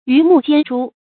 魚目間珠 注音： ㄧㄩˊ ㄇㄨˋ ㄐㄧㄢ ㄓㄨ 讀音讀法： 意思解釋： 見「魚目混珠」。